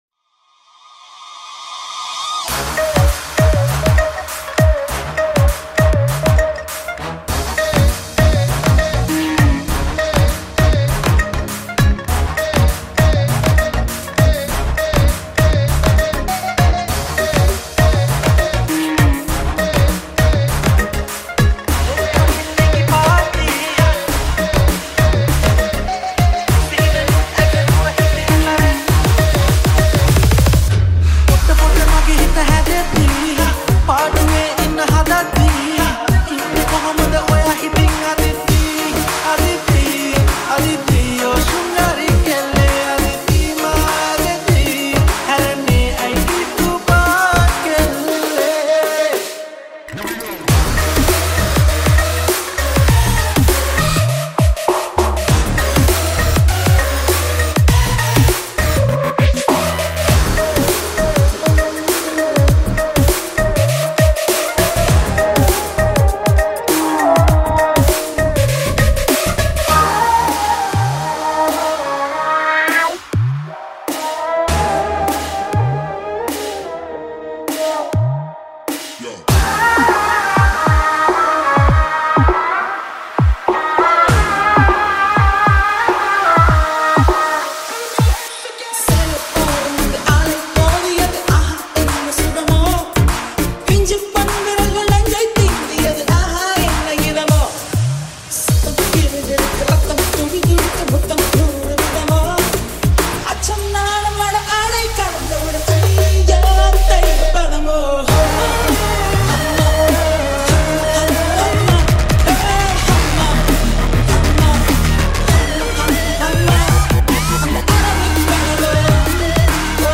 High quality Sri Lankan remix MP3 (3.1).
remix